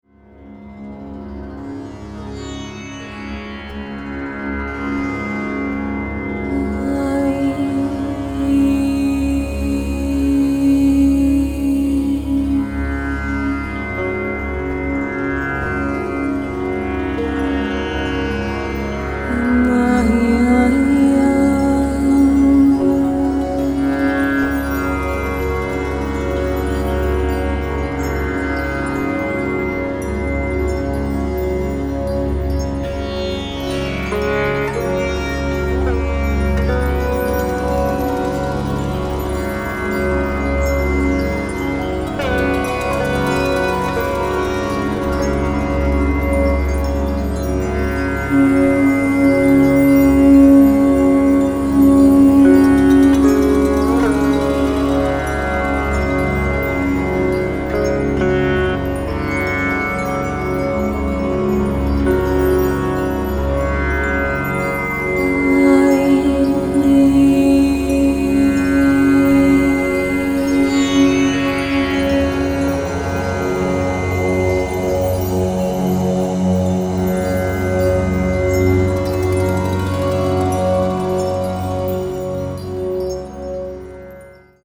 Also features sitar, tambura, Native American Indian flute